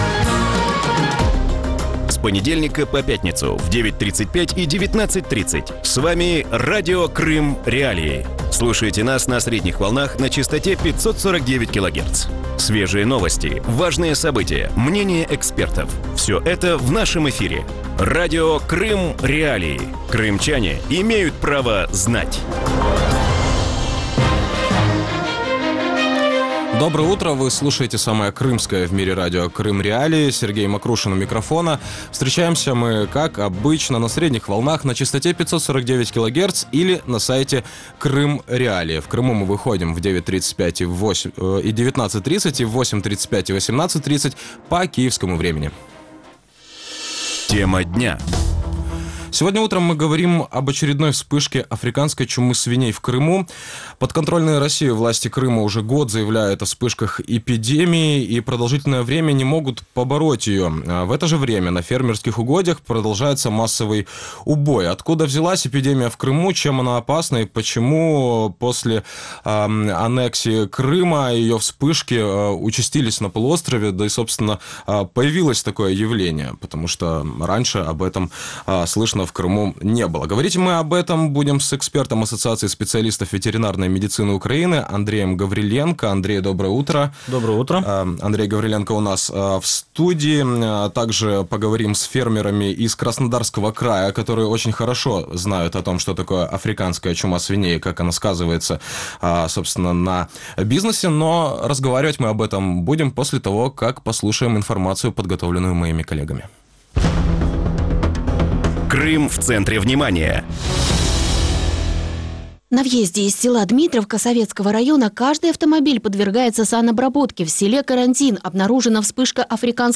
В утреннем эфире Радио Крым.Реалии говорят об очередной вспышке африканской чумы свиней в Крыму.